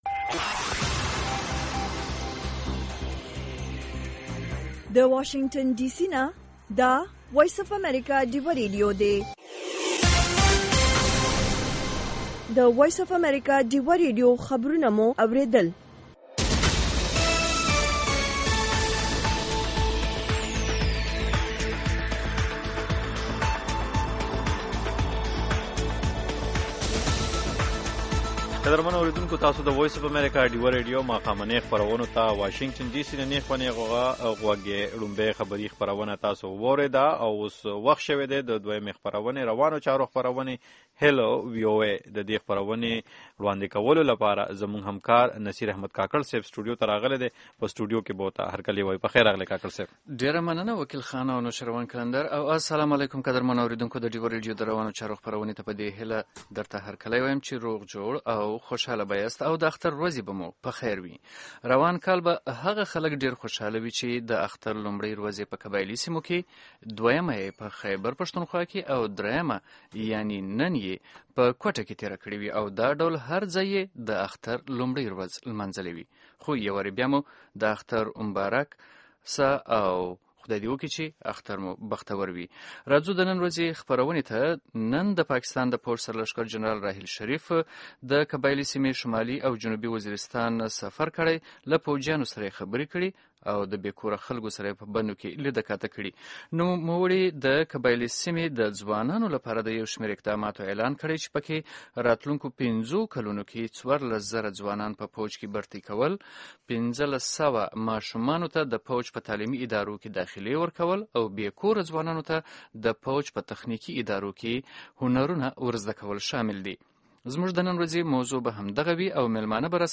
د پاکستان د پوځ سرلښکر جنرال راحيل شريف د قبايلي سیمو د ځوانانو لپاره د "فاټا يوتهـ پېکج" په نامه ونډه اعلان کړی چی له لوري به یې څوارلس زره ځوانان په پوځ کی و ګمارل شي او پنځه لس سوه ماشومانو ته به په پوځي ښونځیو کې د زدکړولپاره داخلې ورکول شي. د ډیوه ریډیو د روانوچاروپه خپرونه کې څیړونکو وویل چي که څه هم دا یو ښه ګام دۍ خو عارضی دی.